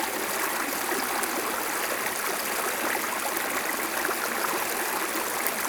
sfx_river.wav